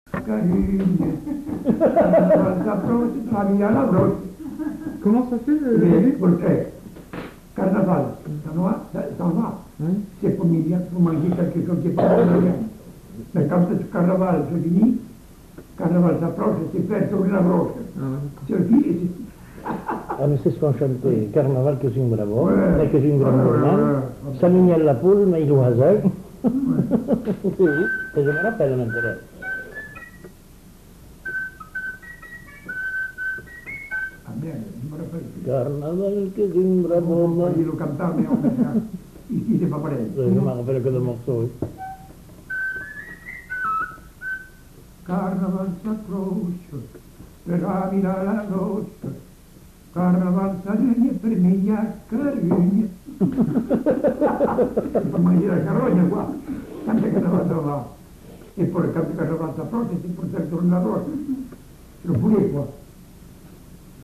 Aire culturelle : Bazadais
Genre : chant
Effectif : 1
Type de voix : voix d'homme
Production du son : chanté
Classification : chanson de carnaval